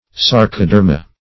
sarcoderma - definition of sarcoderma - synonyms, pronunciation, spelling from Free Dictionary
Search Result for " sarcoderma" : The Collaborative International Dictionary of English v.0.48: Sarcoderm \Sar"co*derm\, sarcoderma \sar`co*der"ma\, n. [NL. sarcoderma.
sarcoderma.mp3